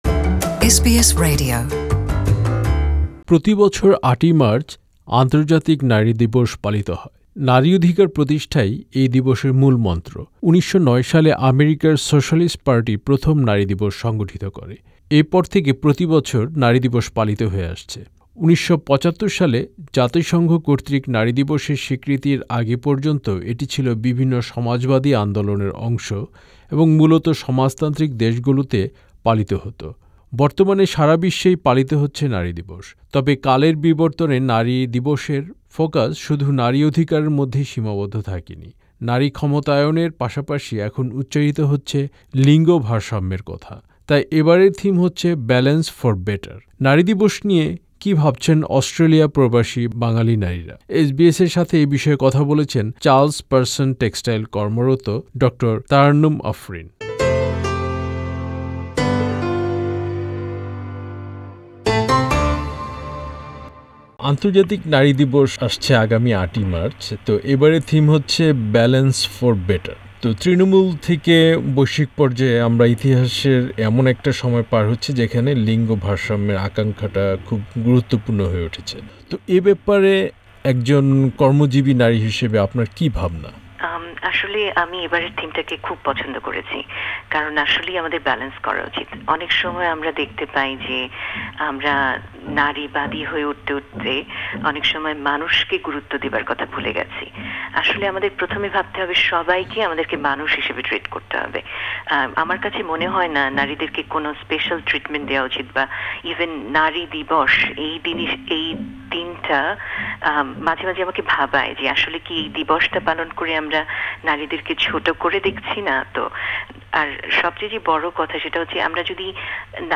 Supplied বাংলায় তাদের সাক্ষাৎকারগুলো শুনতে উপরের অডিও প্লেয়ারটিতে ক্লিক করুন।